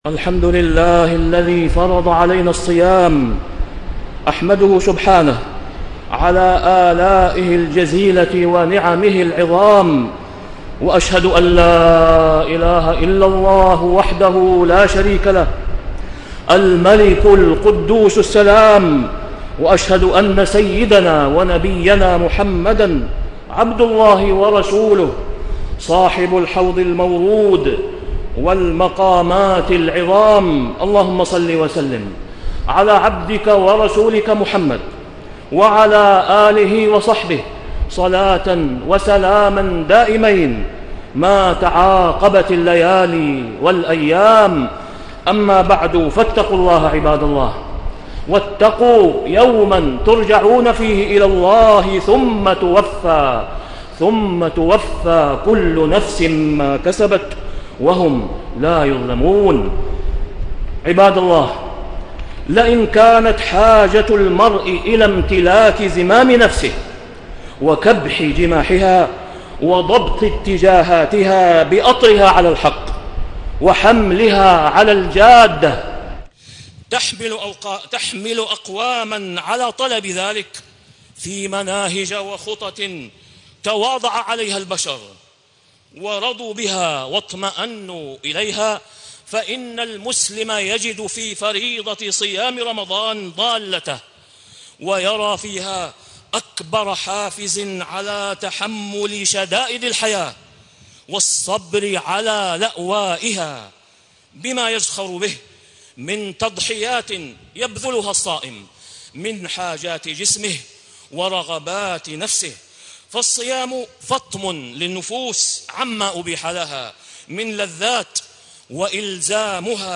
تاريخ النشر ١٠ رمضان ١٤٣٤ هـ المكان: المسجد الحرام الشيخ: فضيلة الشيخ د. أسامة بن عبدالله خياط فضيلة الشيخ د. أسامة بن عبدالله خياط رمضان شهر التضحيات